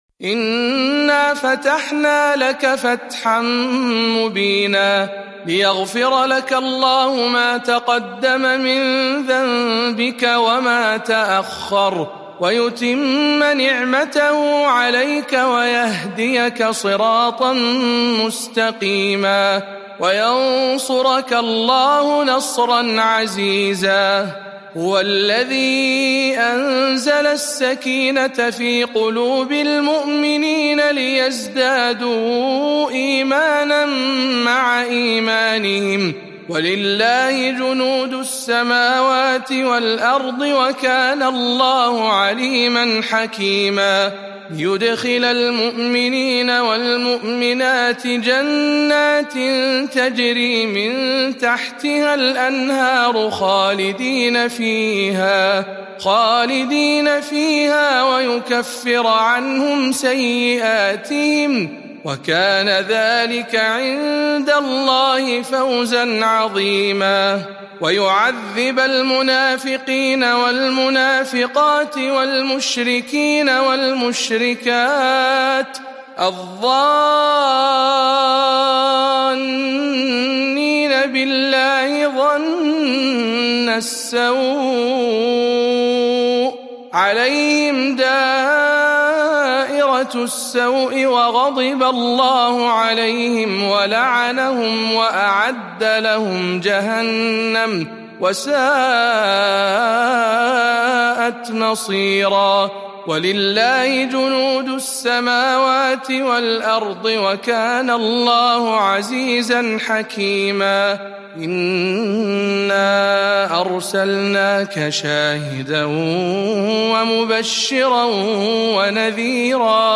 047. سورة الفتح برواية شعبة عن عاصم